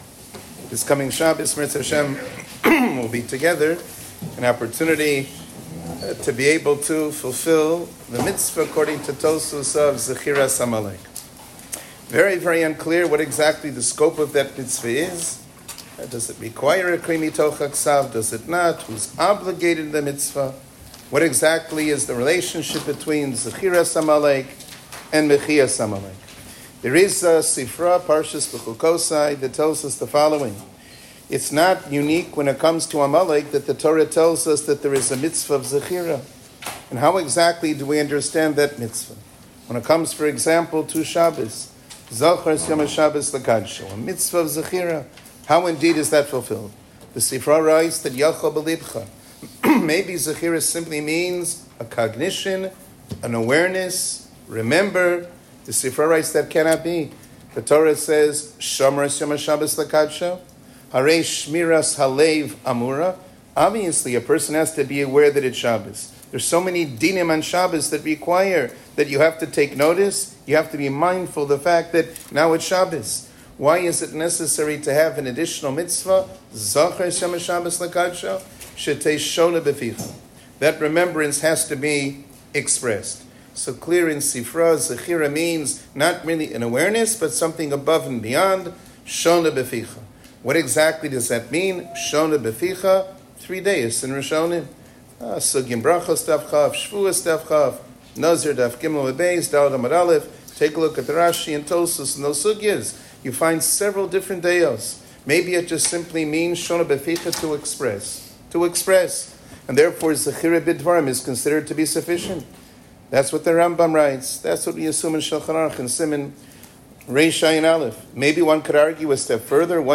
שיעור כללי - מצות זכירת עמלק